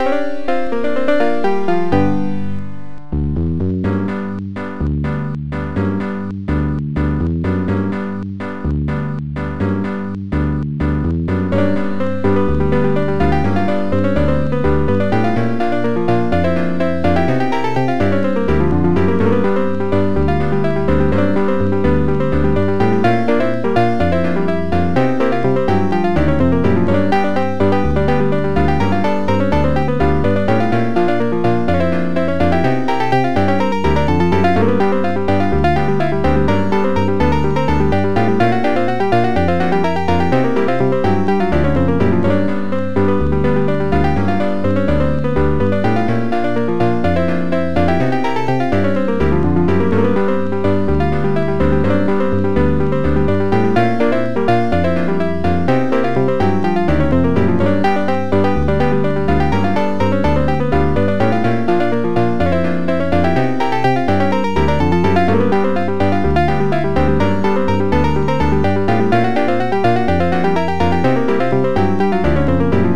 Protracker Module
st-18:PSS-170-Bass st-17:S220-Piano